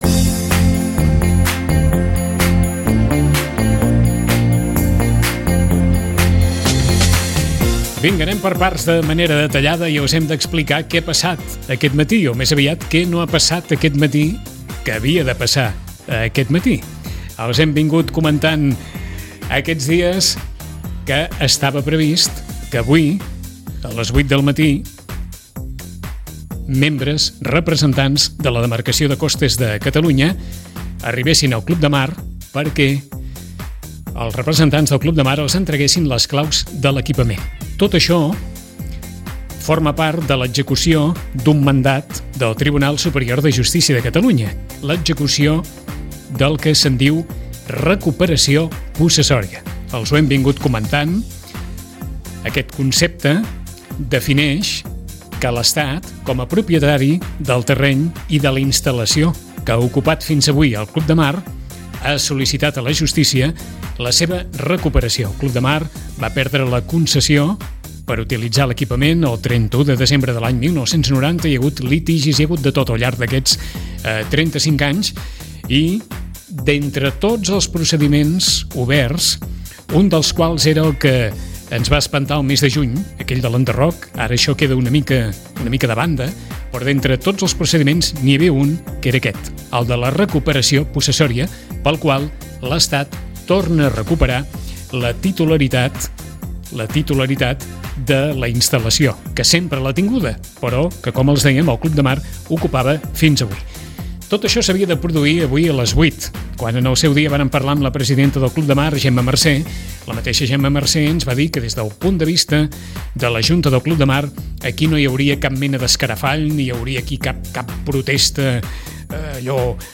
Us oferim la crònica completa d’aquest matí kafkià, amb declaracions que us ajudaran a contextualitzar la situació.